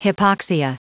Prononciation du mot hypoxia en anglais (fichier audio)
Prononciation du mot : hypoxia